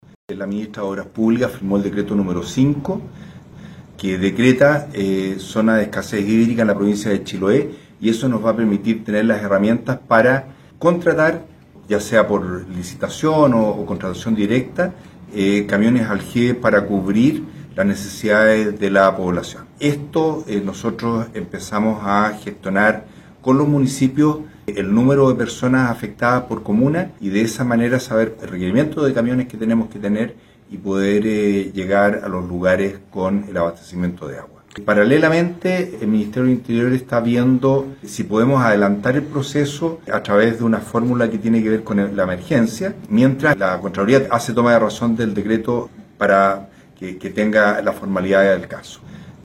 Así lo informó el delegado provincial de Chiloé Marcelo Malagueño, señalando que abarcaría a todas las comunas de la provincia.